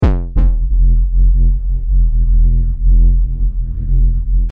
Virus_SubBass5.mp3